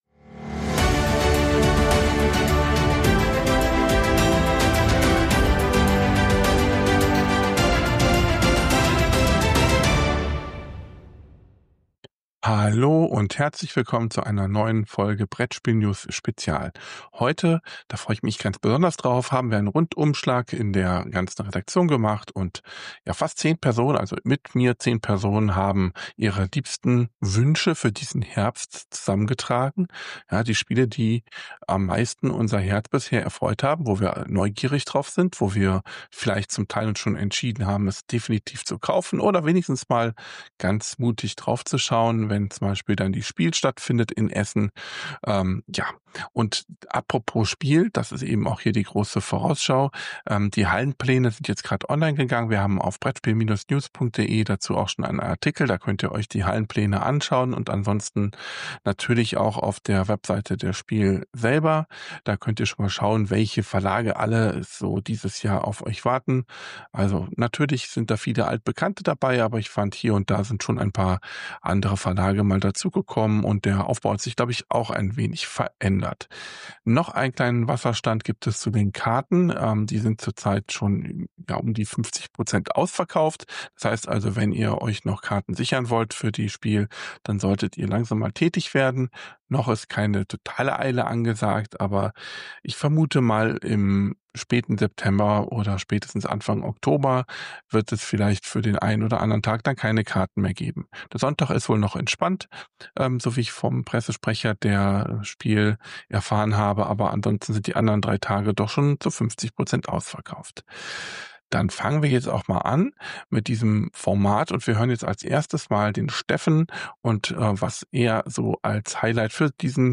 In dieser Episode von Brettspiel-News-Spezial bieten wir einen umfassenden Rückblick auf die herannahende Spielemesse im Herbst, die im berühmten Messegelände in Essen stattfindet. Zehn Mitglieder unserer Redaktion haben sich zusammengefunden, um ihre mit Spannung erwarteten Spiele des Herbstes vorzustellen. Wir analysieren, welche Neuheiten die Spieleszene dominieren werden und welche Spiele wir unbedingt auf unseren Tisch bringen möchten.